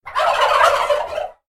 دانلود صدای بوقلمون از ساعد نیوز با لینک مستقیم و کیفیت بالا
جلوه های صوتی